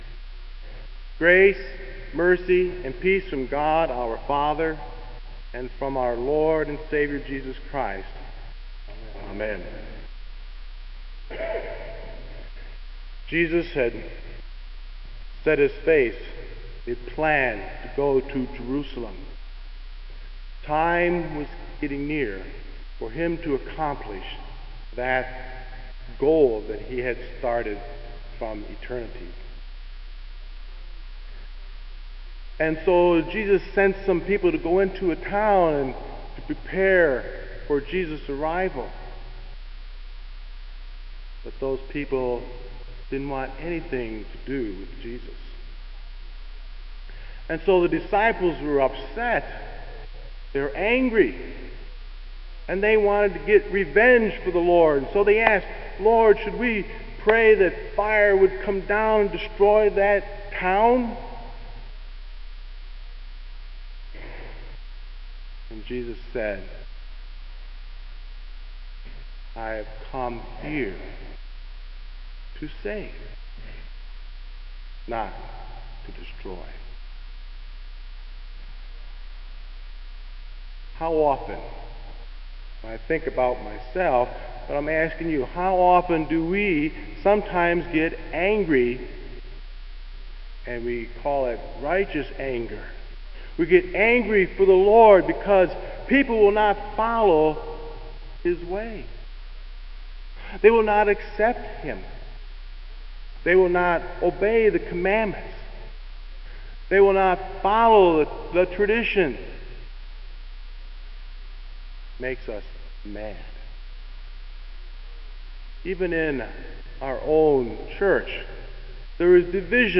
Kramer Chapel Sermon - July 03, 2001